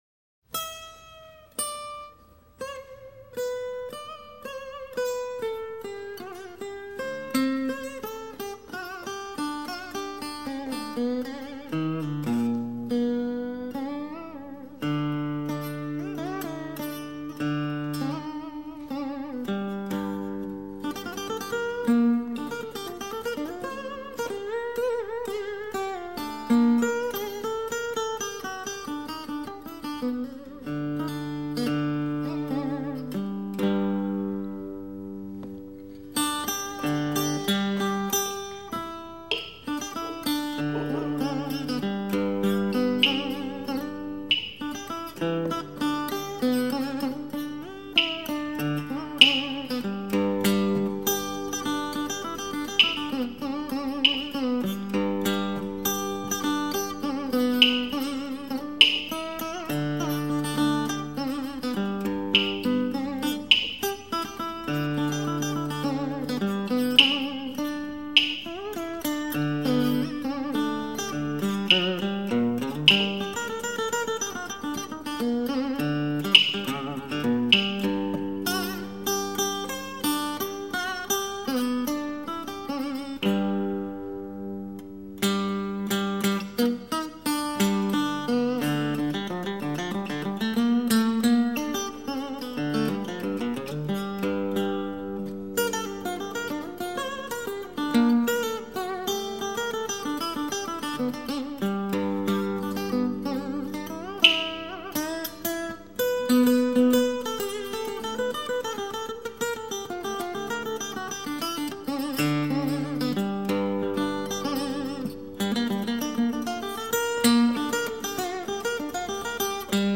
不论是情歌、民谣或创作曲，都丝毫不受音乐语法的拘束，在随兴、现代的色彩中散出迷人的民间音乐韵味。